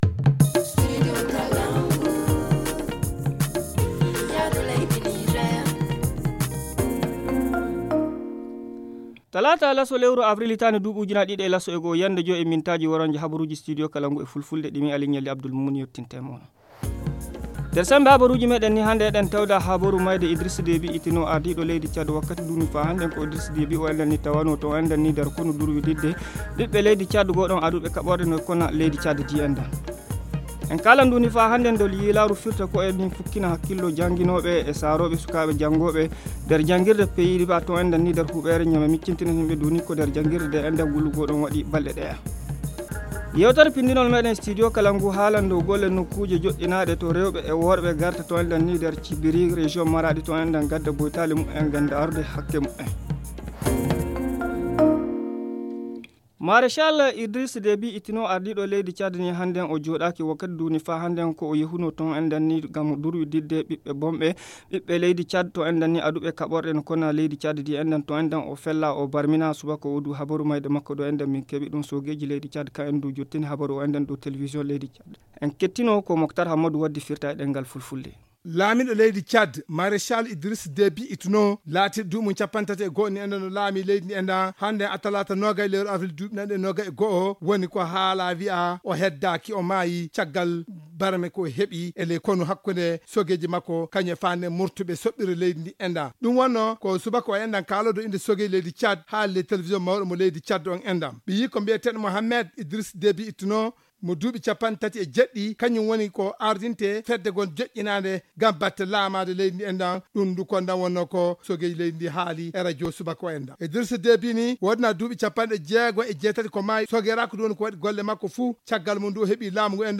Le journal du 20 avril 2021 - Studio Kalangou - Au rythme du Niger